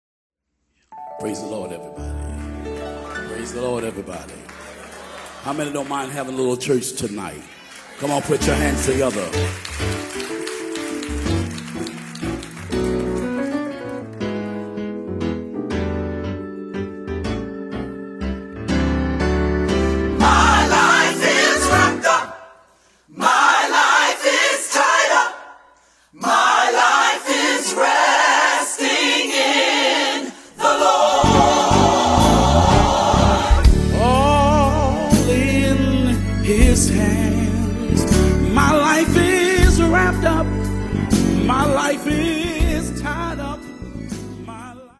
Live at the South Orange Performing Arts Center.